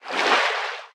Sfx_creature_babypenguin_swim_glide_04.ogg